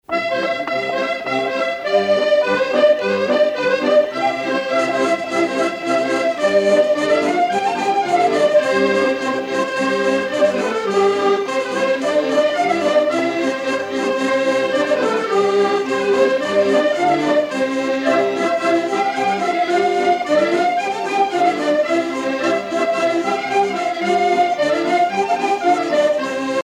danse : rondeau
Pièce musicale éditée